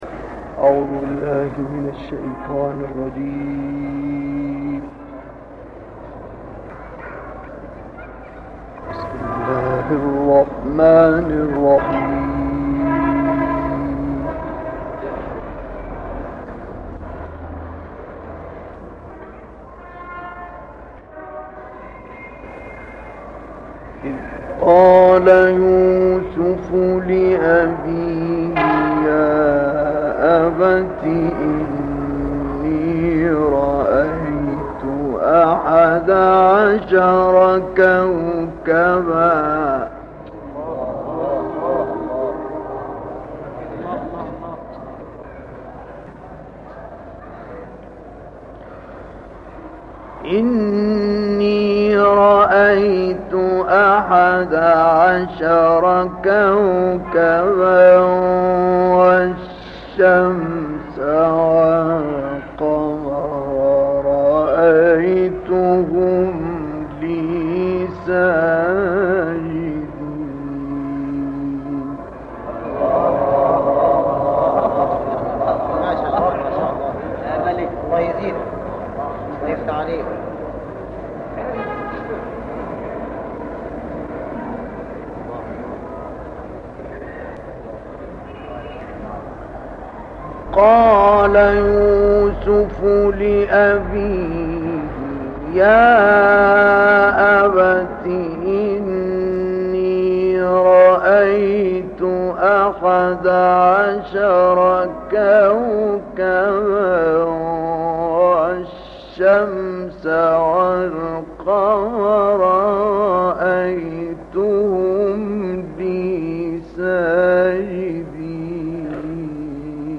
القرآن الكريم - الكوثر: تلاوة رائعة للقارئ الشيخ مصطفى اسماعيل من سورة يوسف (4-34) والحاقة (1-12) تلاها عام 1958 في مدينة الاسكندرية المصرية.